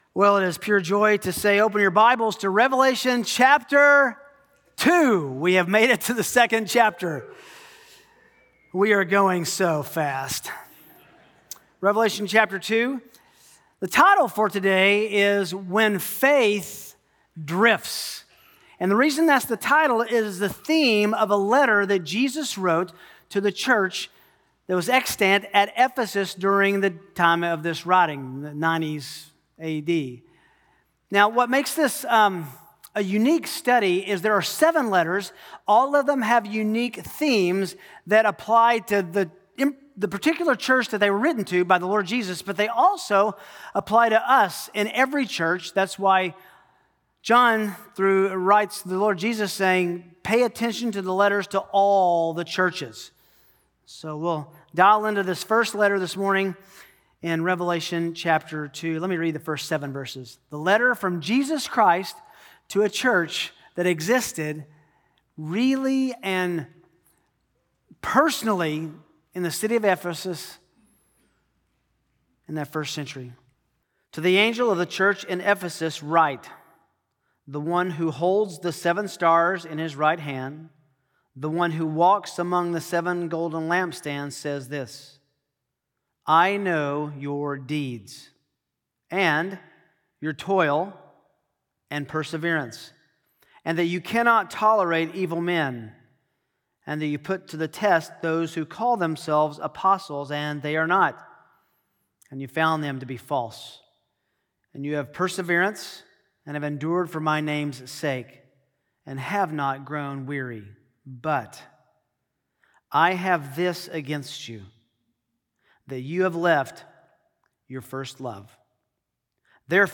Sermons Podcast - When Faith Drifts: Jesus' Letter to Ephesus | Free Listening on Podbean App